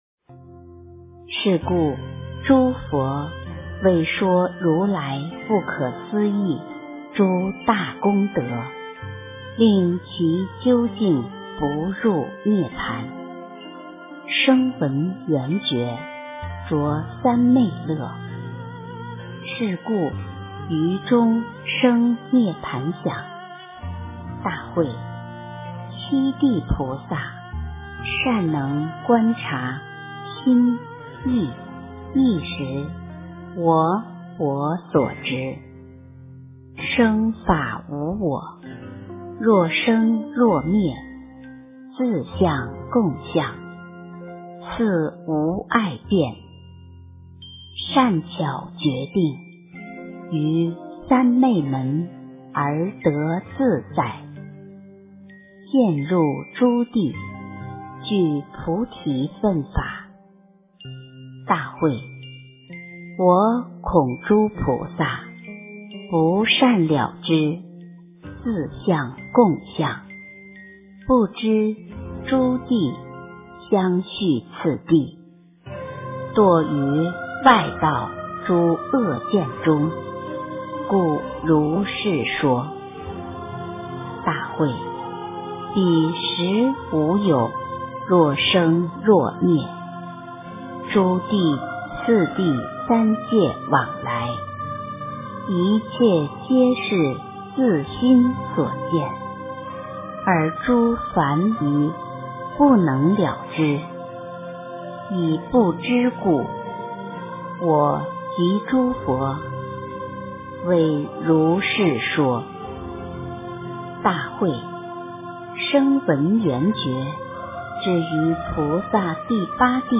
《楞伽经》第五卷下 - 诵经 - 云佛论坛